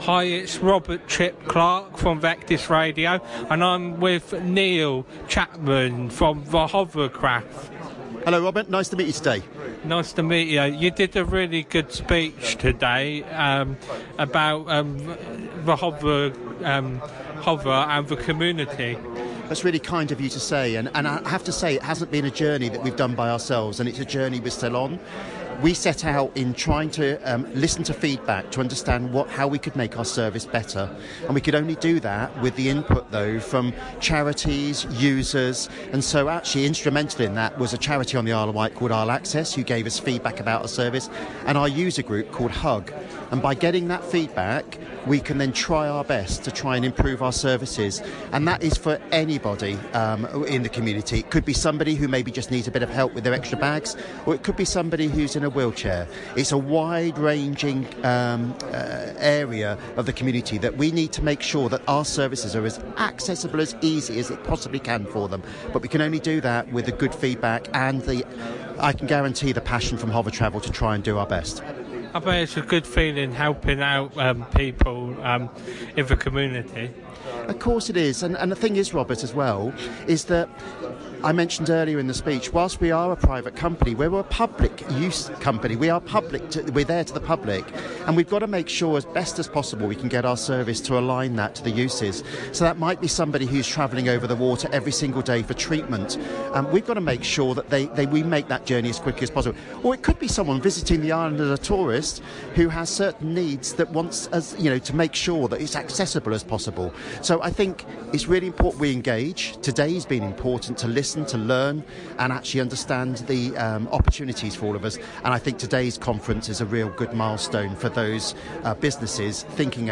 at The Inclusive Island Conference
Hovertavel Interview 2023